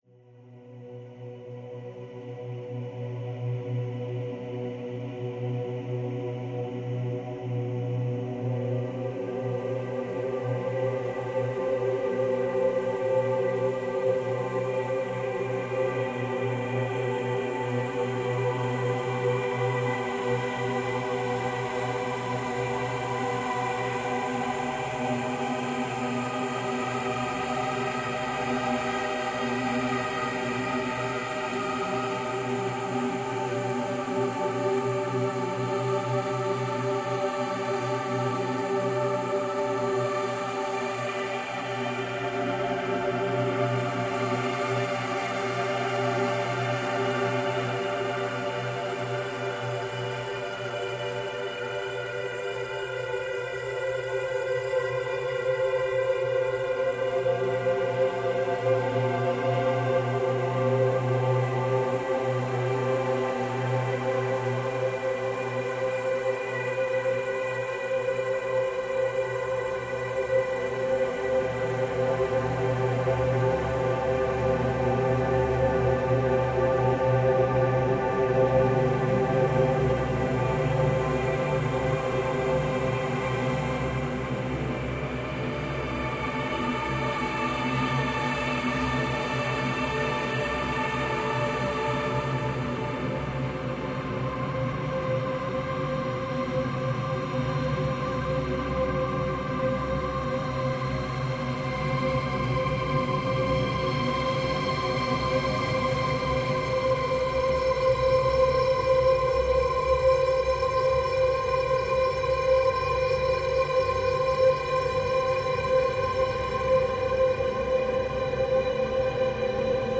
っていうのは置いといて、ヒーリング系ミュージックやホラーのバックとかに使えそうなアンビエント音楽素材です。